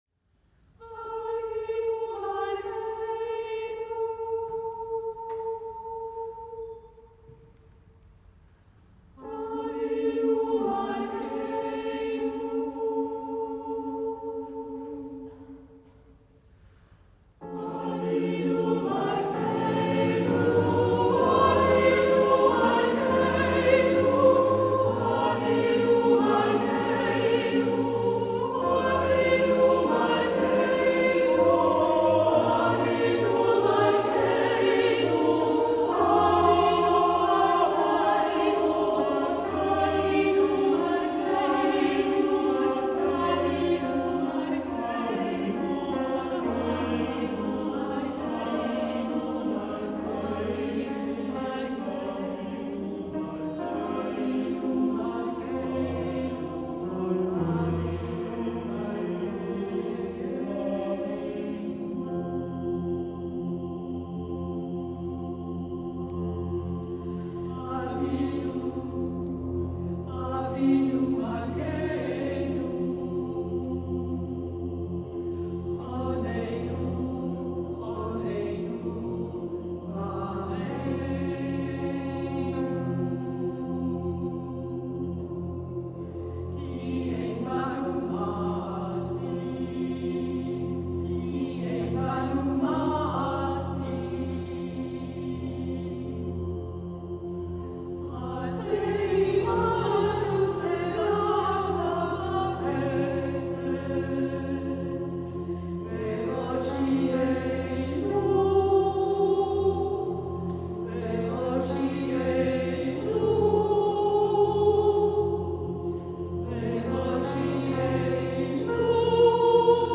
Nur Klavierbegleitung